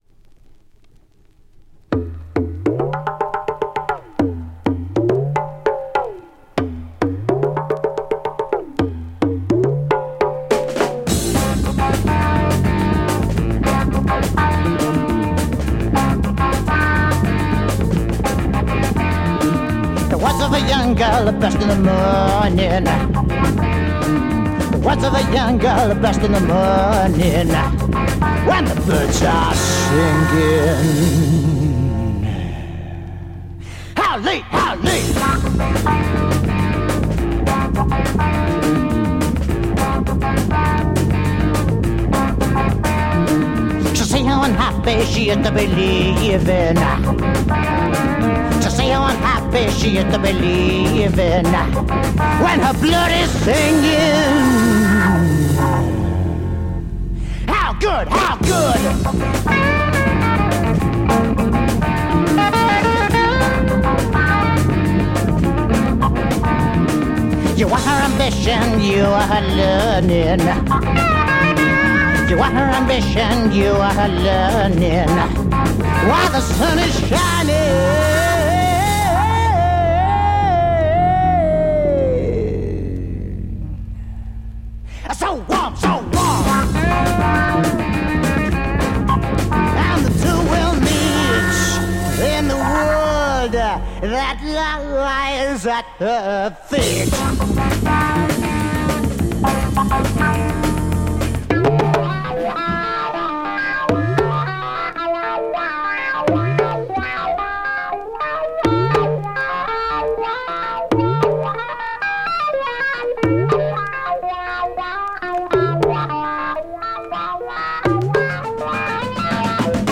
Hard soul rock